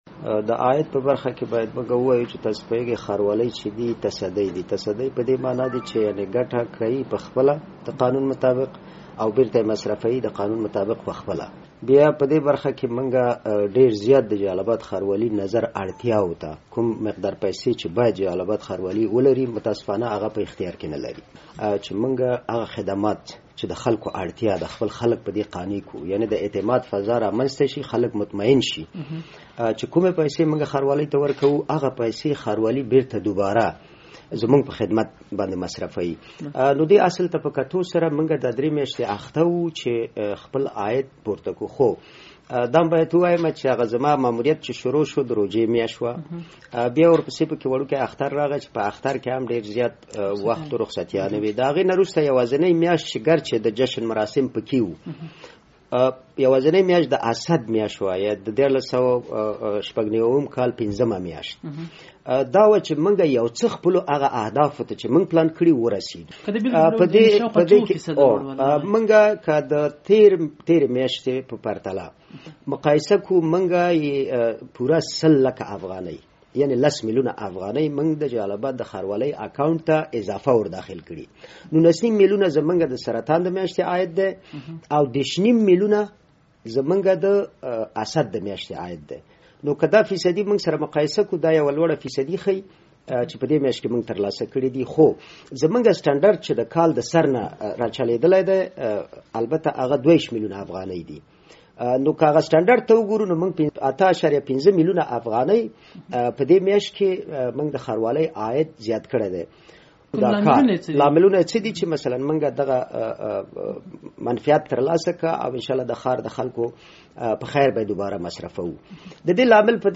د ننګرهار ښاروالۍ له سرپرست ښاغلي حکیم الدین سره مرکه